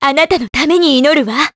Juno-Vox_Skill7_jp.wav